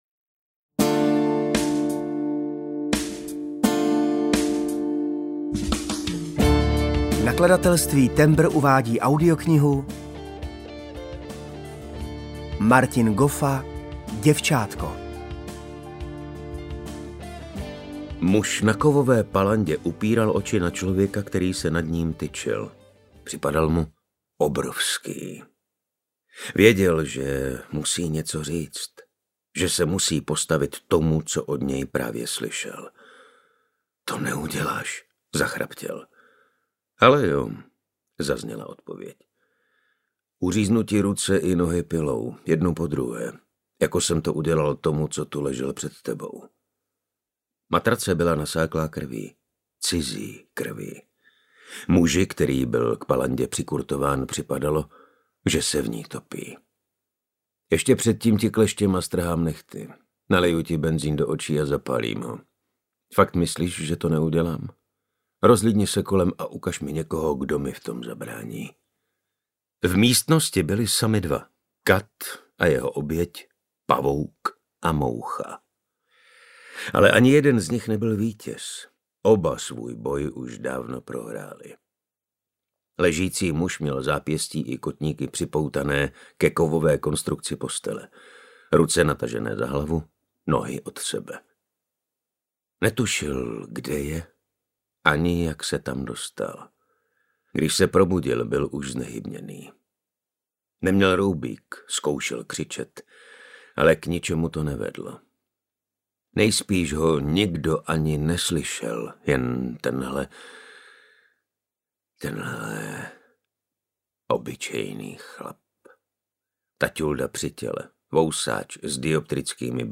Děvčátko audiokniha
Ukázka z knihy
• InterpretJan Maxián, Martin Stránský